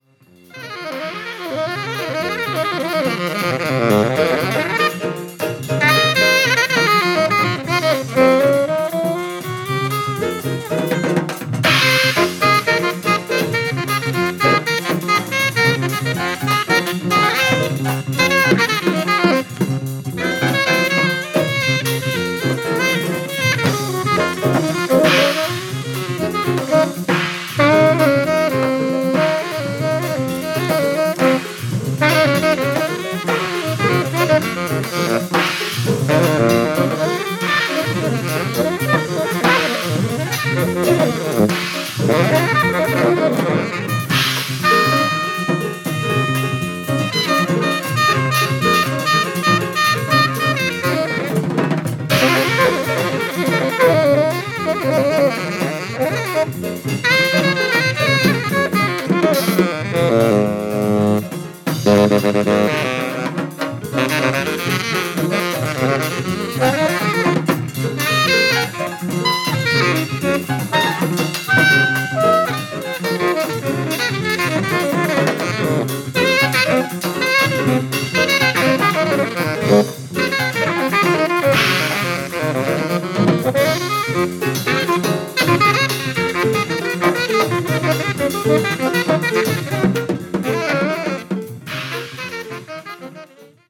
ご機嫌な仲間とシカゴで録音されたアルバムです。